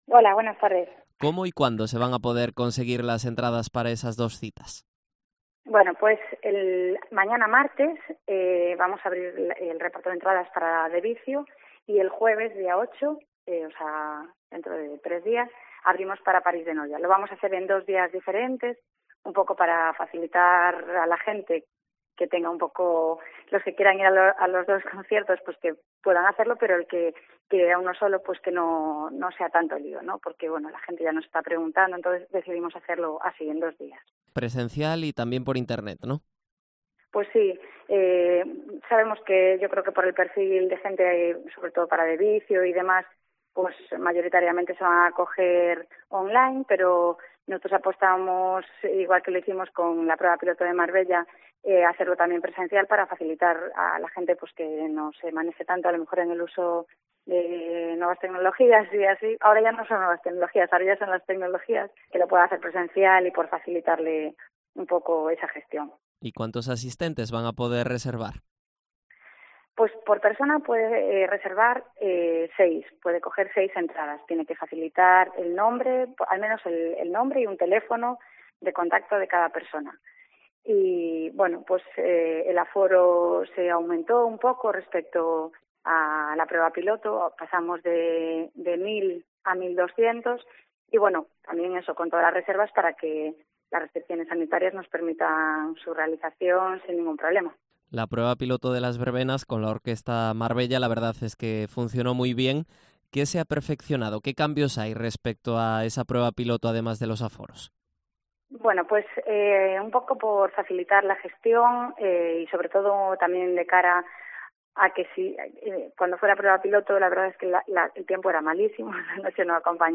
Entrevista a Marián Sanmartín, concejala de Fiestas de Marín